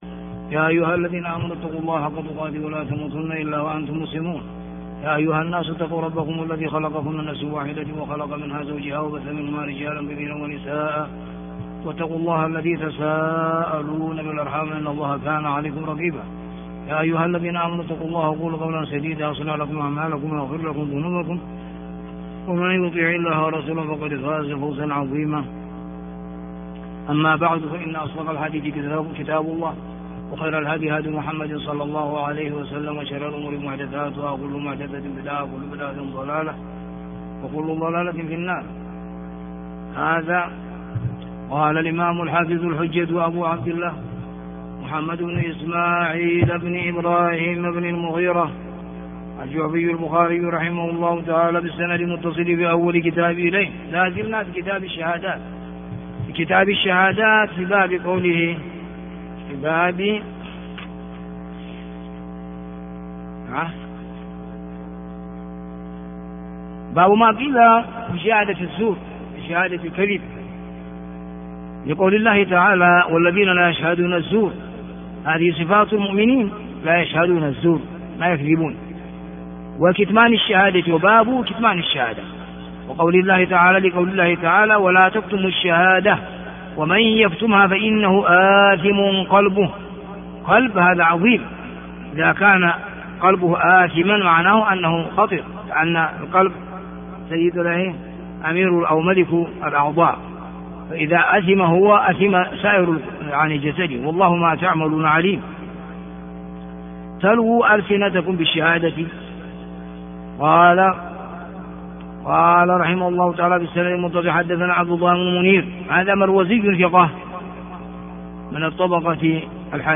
الدرس 182 - كتاب الشهادات - بَابُ مَا قِيلَ فِي شَهَادَةِ الزُّورِ - ح 2653